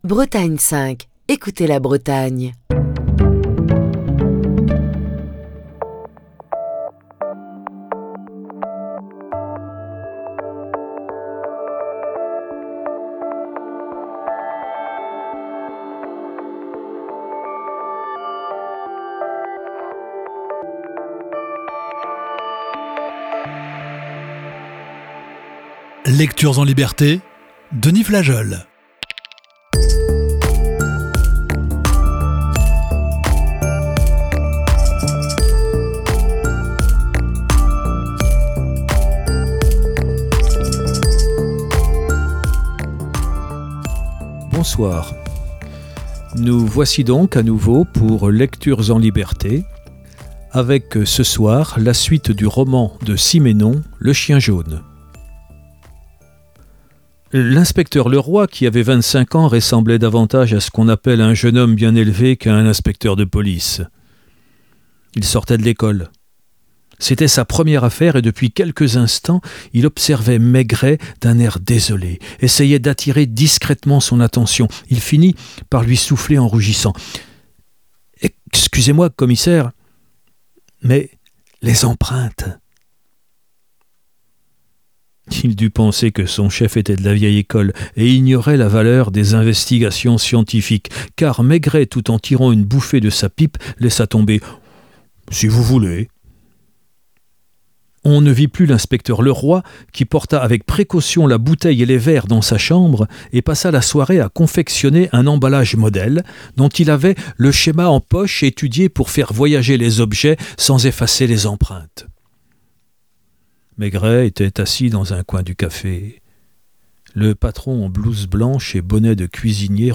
Émission du 9 janvier 2024.